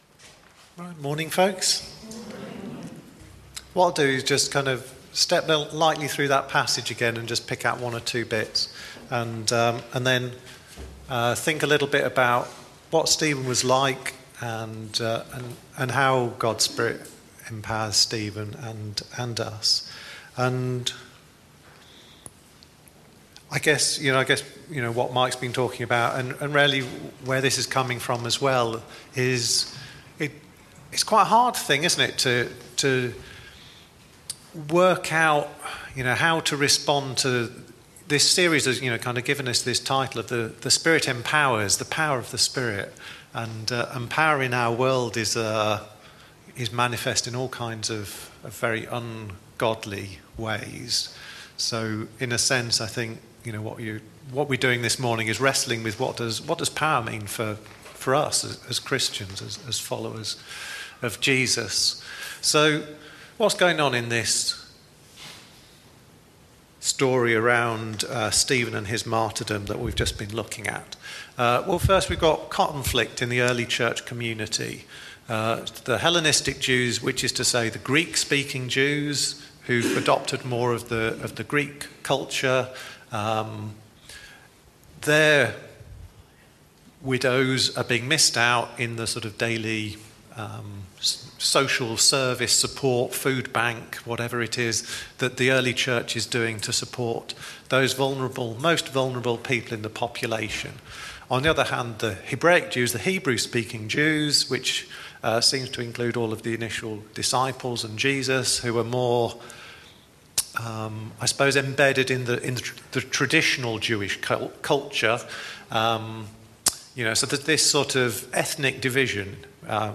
Talks